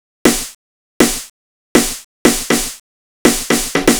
Session 14 - Snare.wav